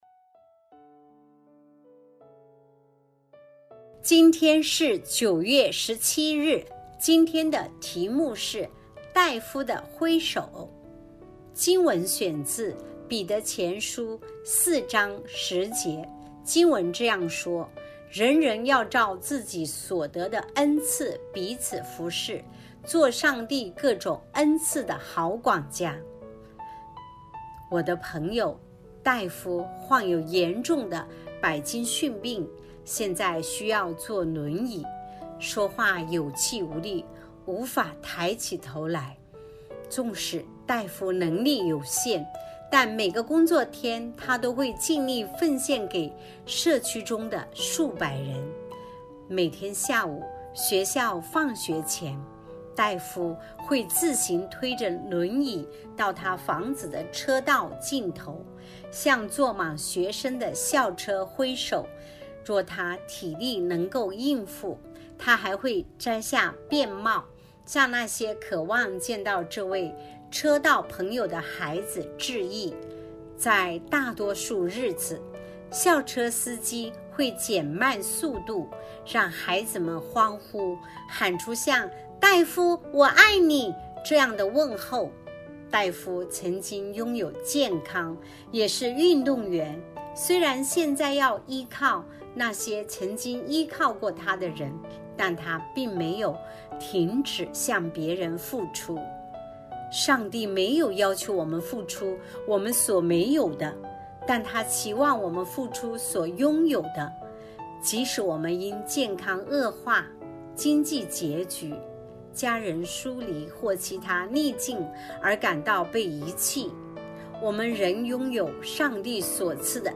循道衞理聯合教會香港堂 · 錄音佈道組 Methodist Outreach Programme
錄音員